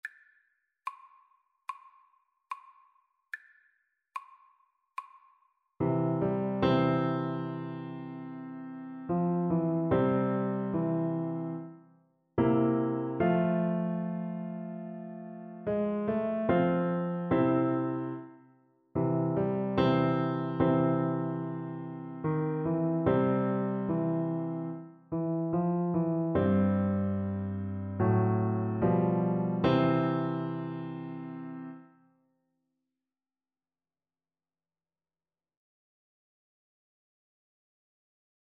Christian
Moderato
C5-D6
4/4 (View more 4/4 Music)